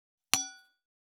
304金属製のワインカップ,ステンレスタンブラー,シャンパングラス,ウィスキーグラス,ヴィンテージ,ステンレス,金物グラス,
効果音厨房/台所/レストラン/kitchen食器
コップ